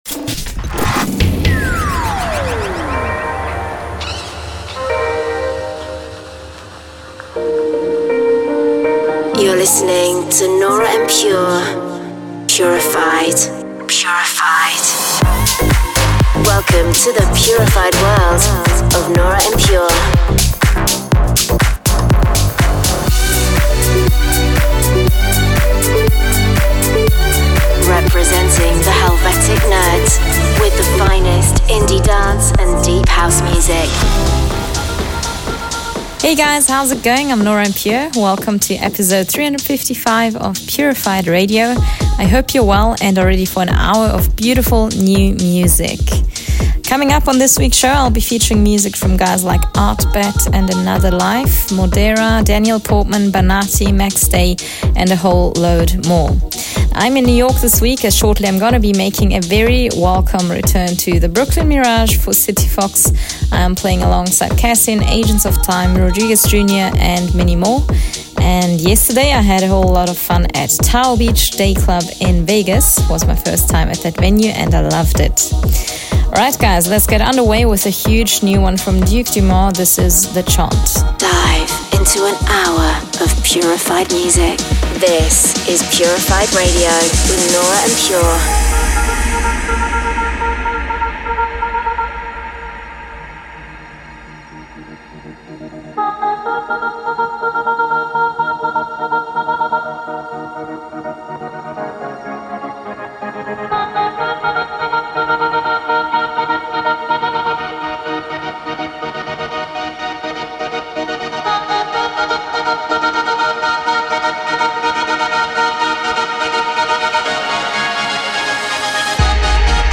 deep house House mix